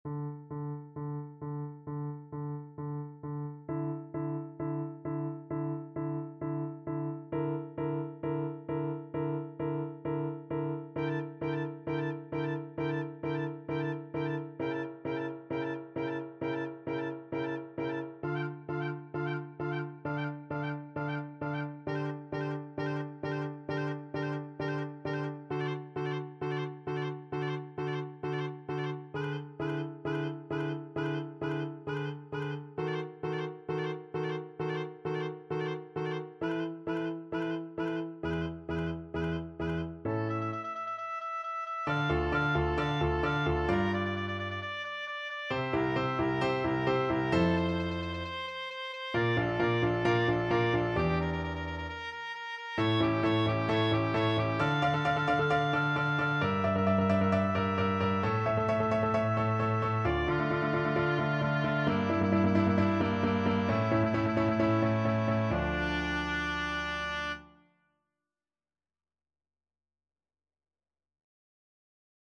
D minor (Sounding Pitch) (View more D minor Music for Oboe )
4/4 (View more 4/4 Music)
Allegro non molto =c.132 (View more music marked Allegro)
Classical (View more Classical Oboe Music)